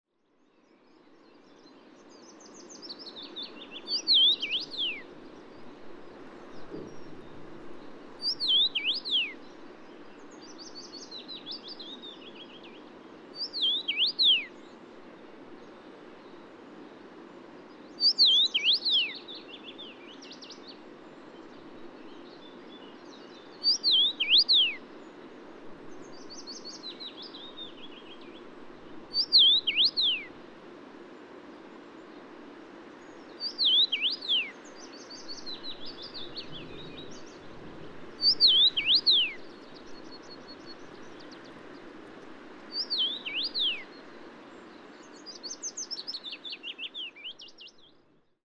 Krkonoše National Park
Ring Ouzel Turdus torquatus alpestris, adult male, song